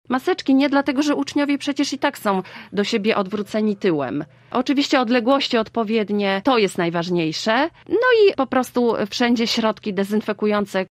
O nietypowej końcówce roku szkolnego mówiła w „Rozmowie Punkt 9” Lubuska Wicekurator Oświaty – Katarzyna Pernal-Wyderkiewicz: